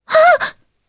Laias-Vox_Damage_jp_02.wav